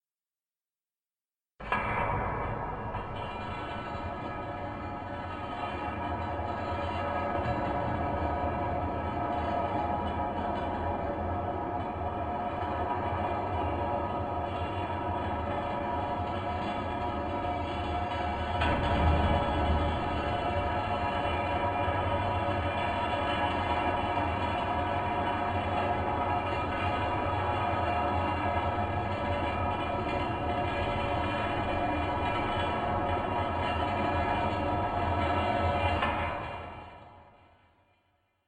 闇の中で金属が地を這いずり、その冷たい呻き声が全身に響き渡ります。